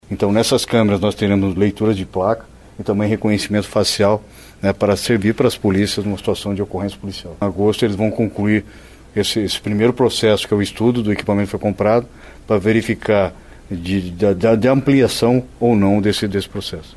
O secretário de Estado de Segurança Pública do Paraná, Hudson Leôncio Teixeira, disse em entrevista coletiva, nesta quarta-feira (19), que os estudos para a possível implementação de câmeras em fardas e viaturas de policiais devem ser concluídos em agosto deste ano.